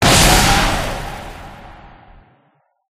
Collapse1.ogg